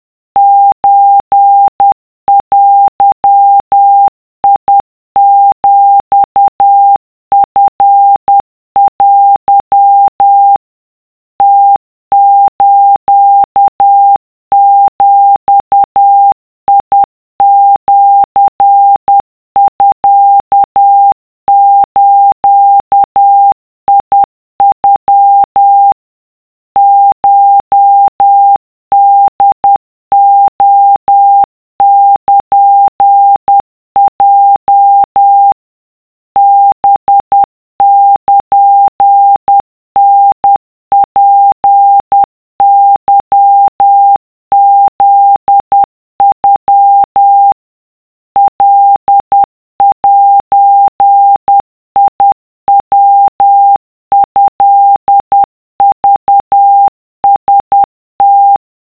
【cw】2【wav】 / 〓古文で和文系〓
とりあえず50CPM (=10WPM)で作ってみた